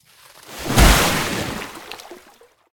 Sfx_creature_snowstalker_jump_water_01.ogg